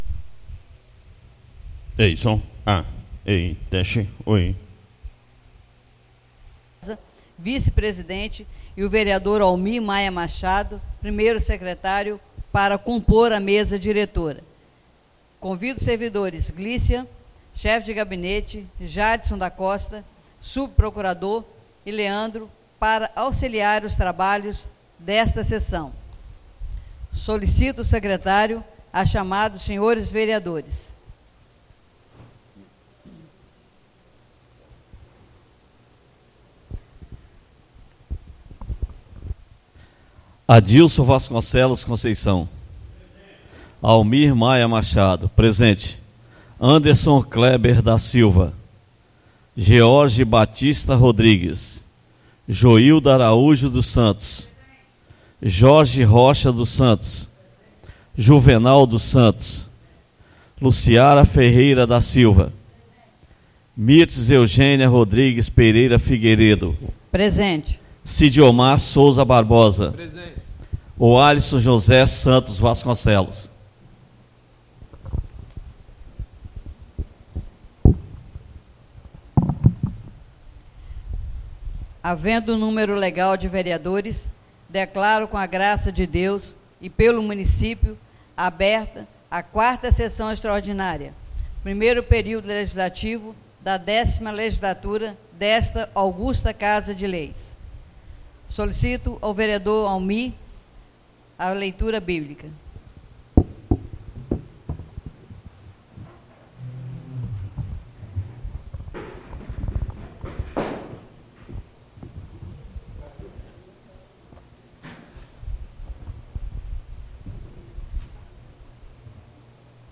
4ª (QUARTA) SESSÃO EXTRAORDINÁRIA 09 DE FEVEREIRO 2017